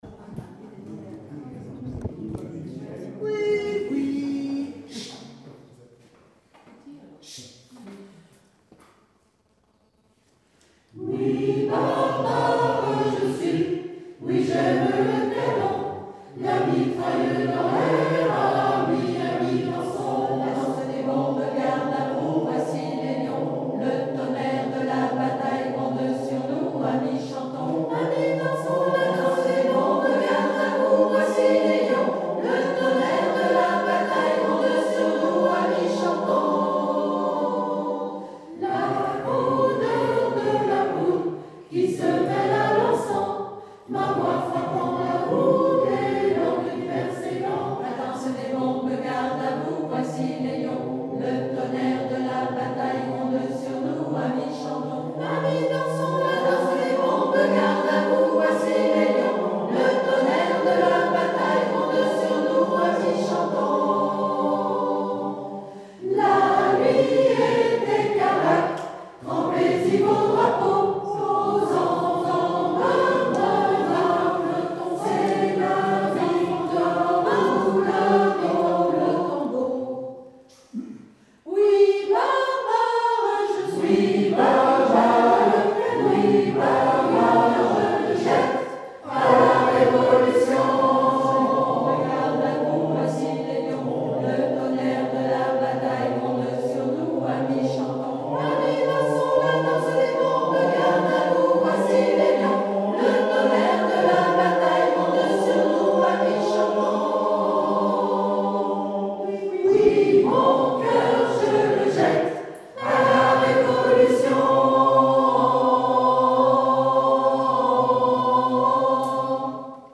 Dansebomb fin stage